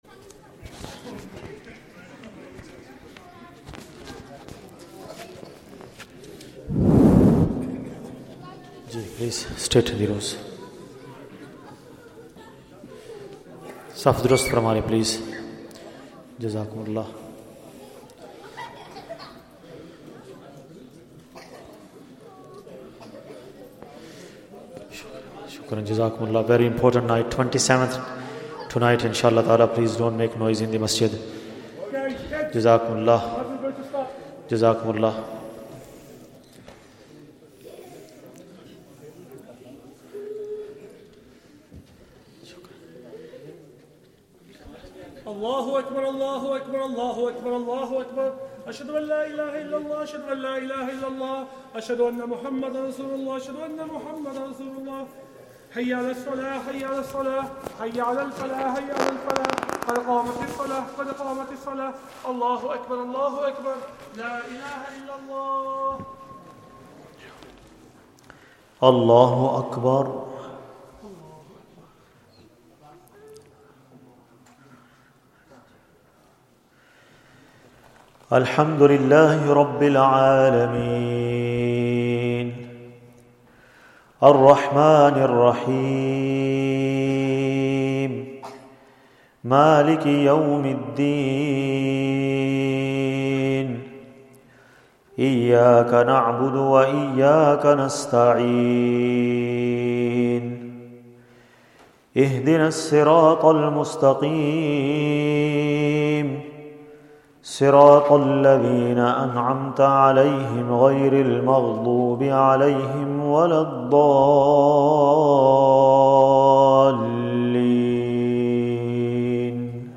Taraweeh 27th night of Ramadhan, juz 29th